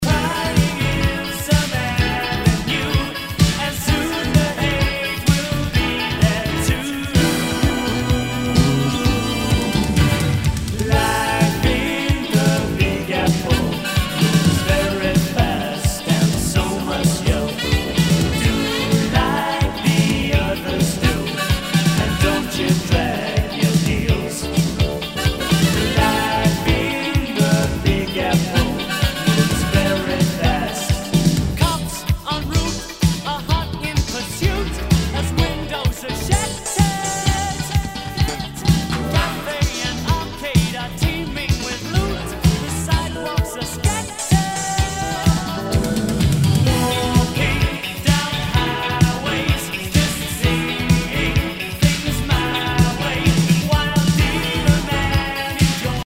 SOUL/FUNK/DISCO
ナイス！シンセ・ポップ・ディスコ！
[VG ] 平均的中古盤。スレ、キズ少々あり（ストレスに感じない程度のノイズが入ることも有り）